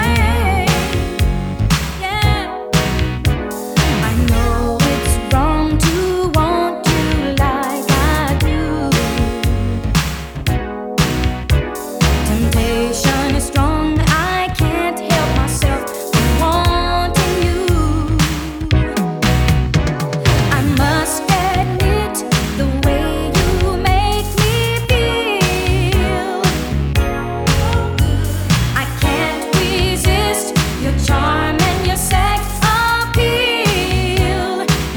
Жанр: R&b / Соул / Фанк